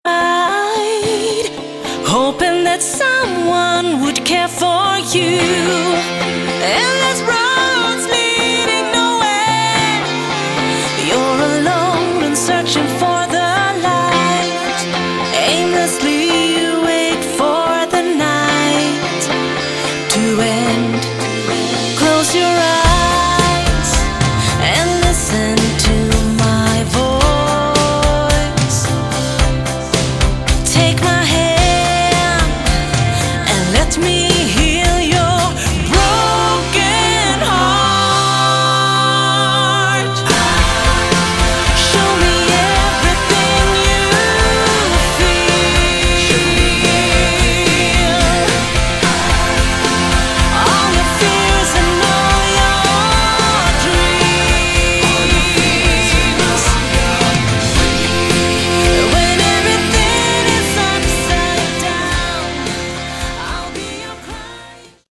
Category: Melodic Rock
lead vocals
guitars, bass
acoustic guitars
keyboards, backing vocals, drums